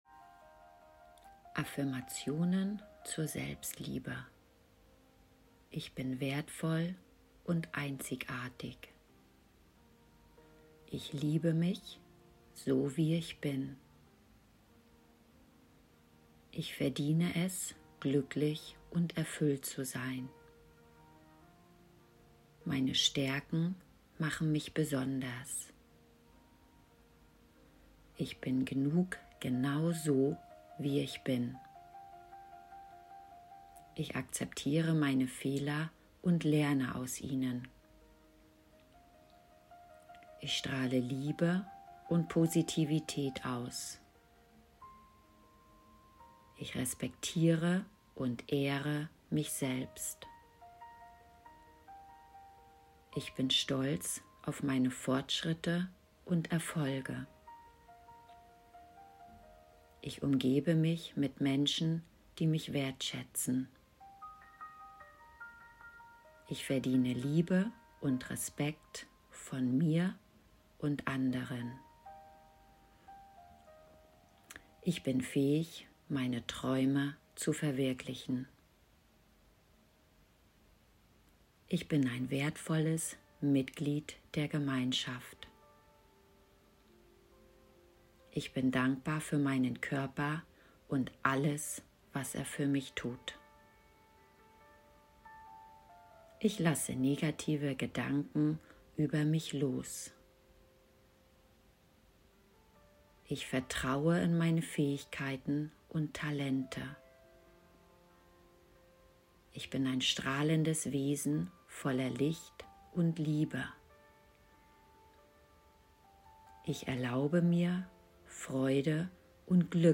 Affirmation zur Selbstliebe (Gratis Audio)